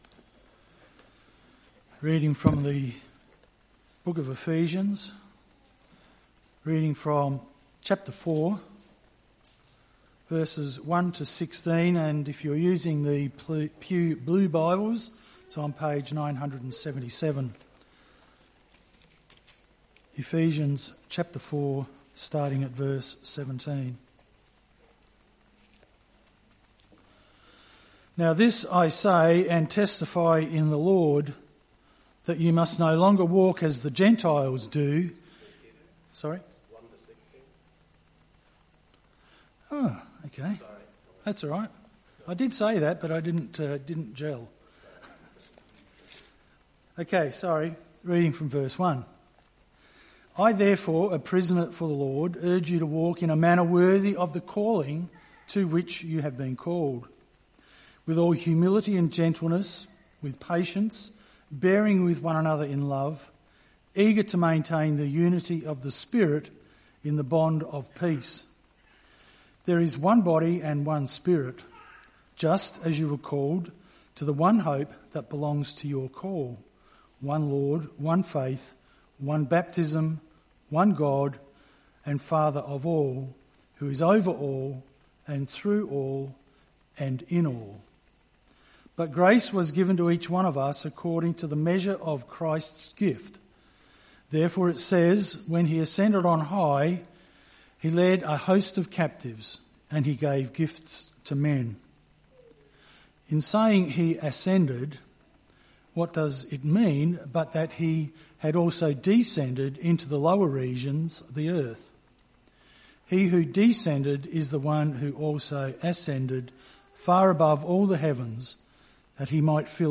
Evening Service - 5th October 2025